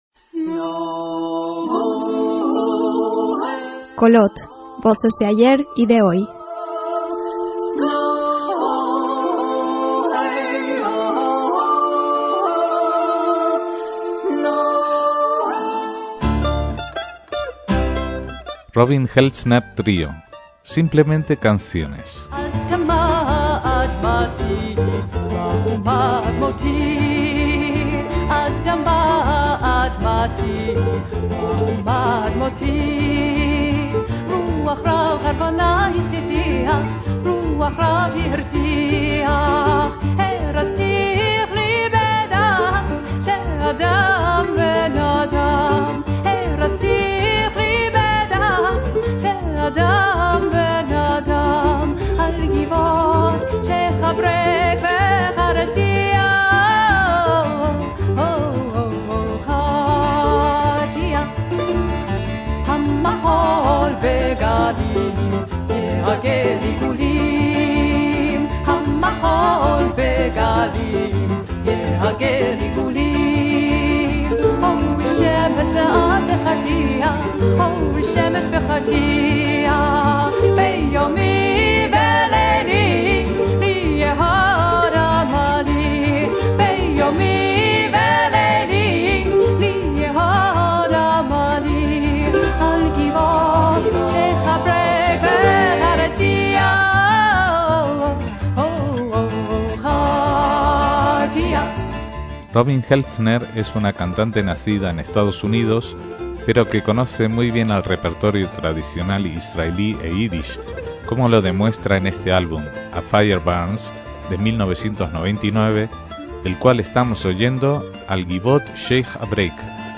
guitarra y mandolina
Ya hace dos décadas que recorren el mundo con canciones sencillas de la tradición judía en su sentido más amplio, de China al festival de música judía de Ámsterdam o Washington.